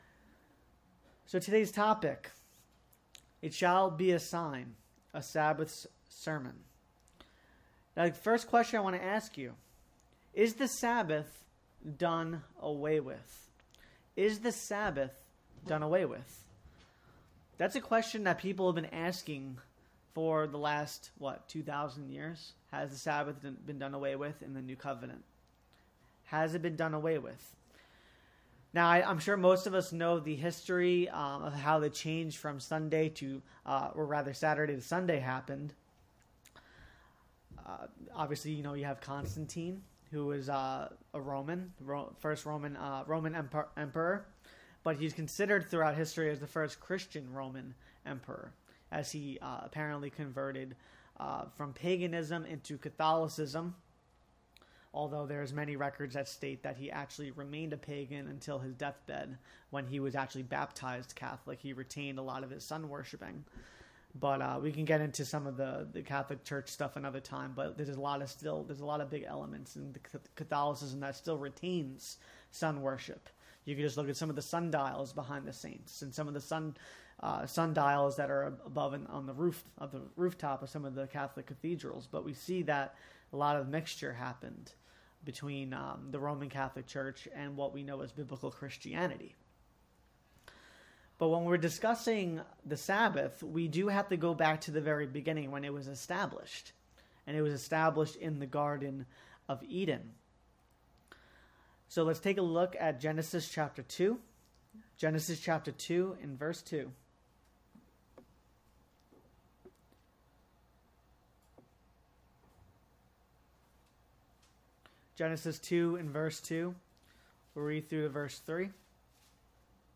God set aside His Sabbath Day as an important period of time each week for His people to come together to worship Him. This sermon goes through a handful of important Sabbath related apologetics.